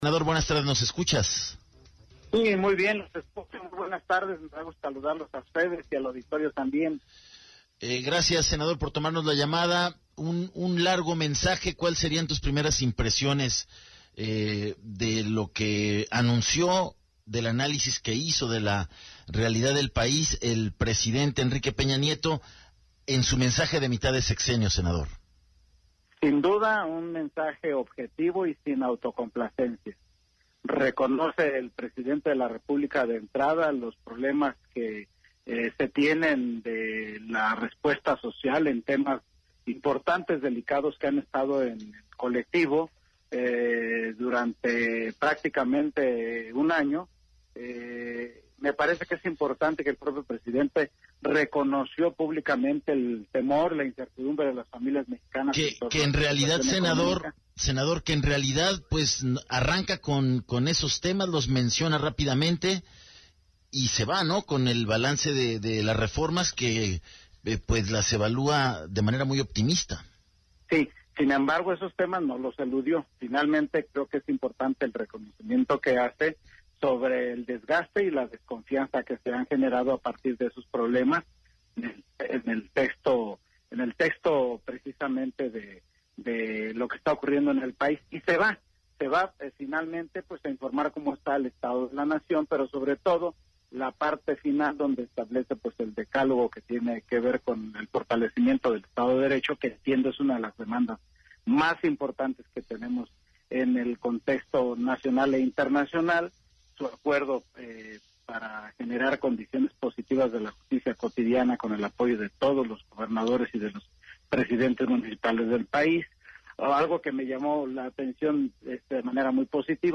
ENTREVISTA 020915
El senador por el PRI, Arturo Zamora habló sobre el III informe de gobierno del presidente Enrique Peña Nieto